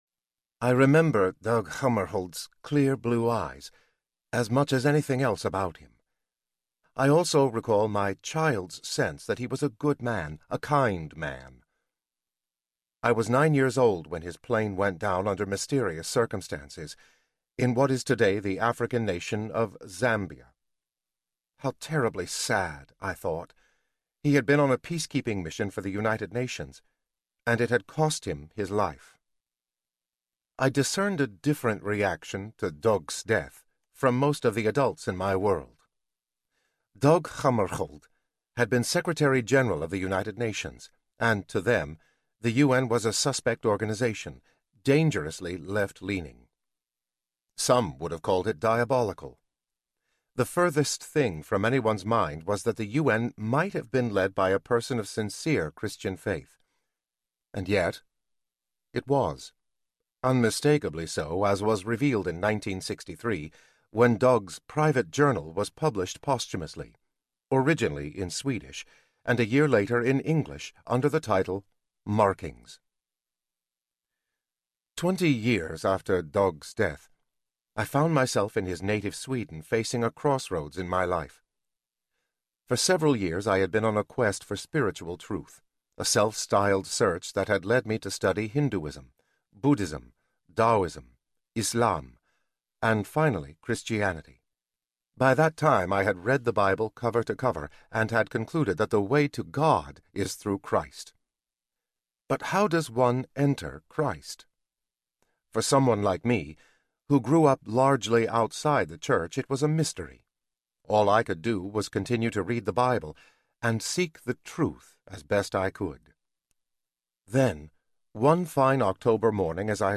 They Were Christians Audiobook
Narrator
6.1 Hrs. – Unabridged